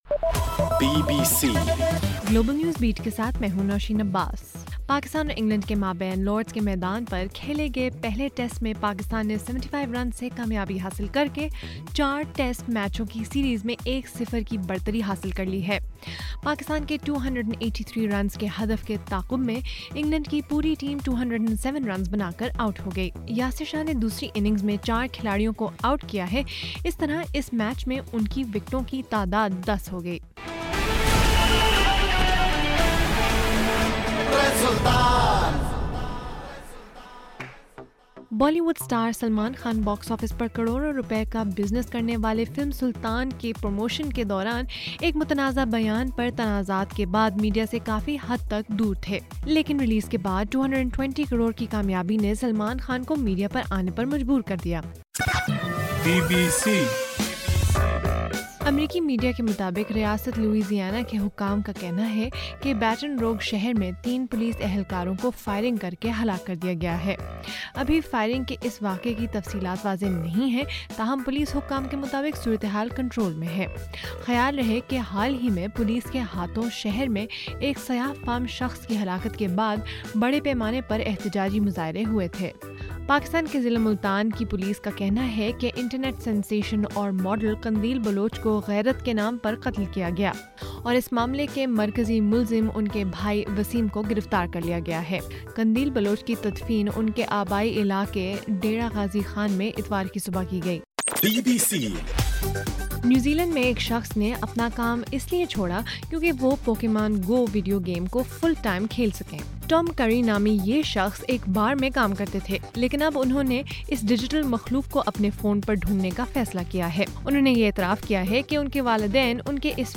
بُلیٹن